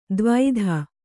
♪ dvaidha